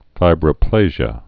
(fībrə-plāzhə)